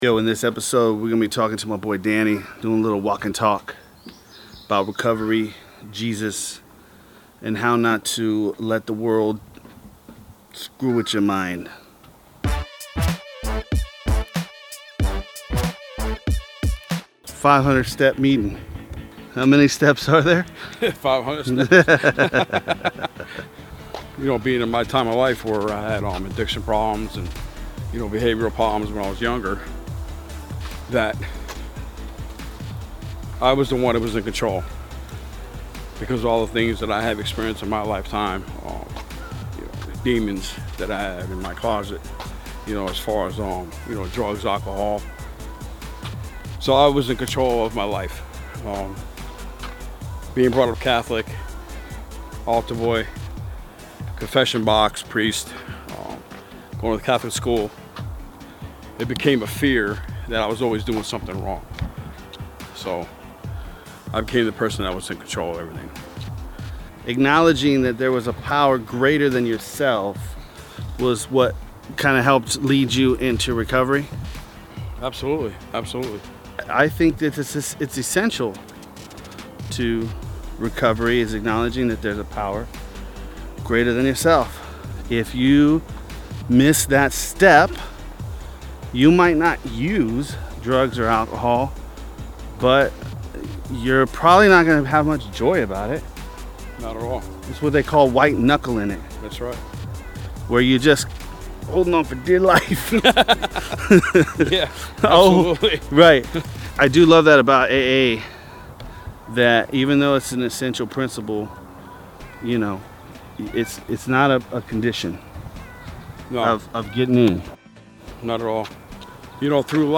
This episode captures a candid conversation between 2 recovering Jesus following drug addicts.